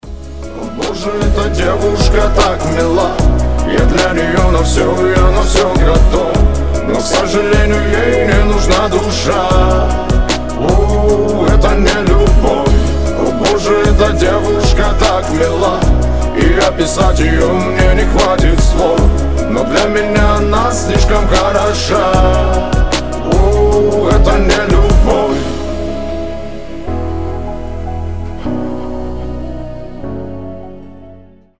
мужской голос
грустные